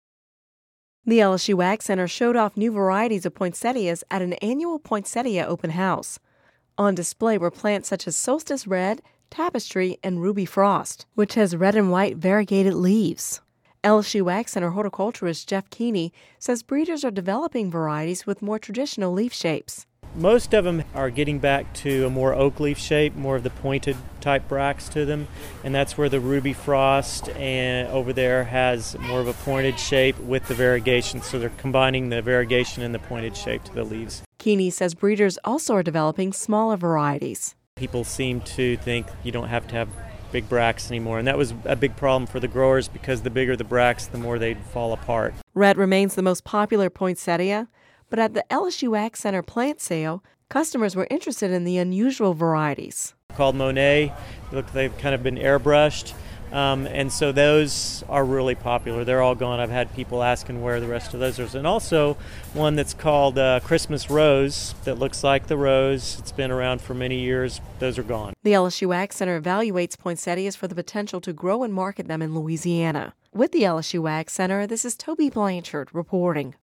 (Radio News 12/20/10) The LSU AgCenter recently showed off new varieties of poinsettias at an annual poinsettia open house. On display were plants such as Solstice Red, Tapestry and Ruby Frost, which has contrasting red and white bracts.